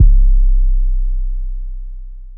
TheSix808_YC.wav